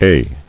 [eh]